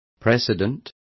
Complete with pronunciation of the translation of precedents.